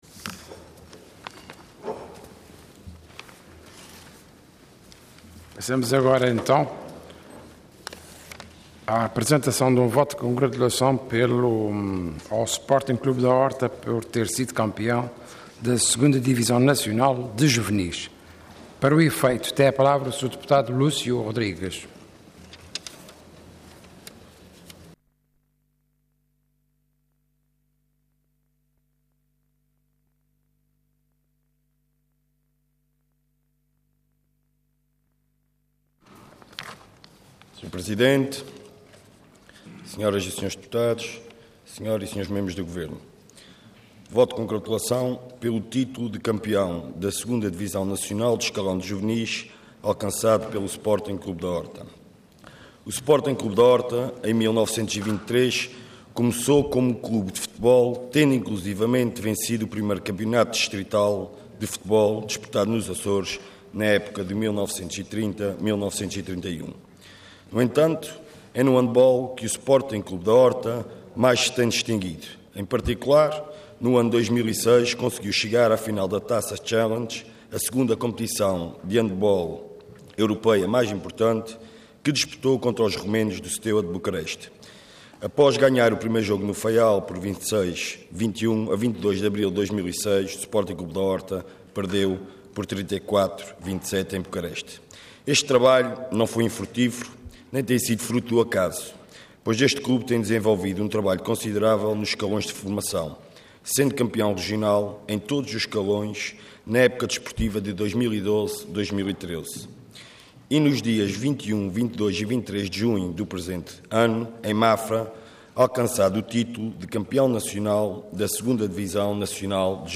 Intervenção Voto de Congratulação Orador Lúcio Rodrigues Cargo Deputado Entidade PS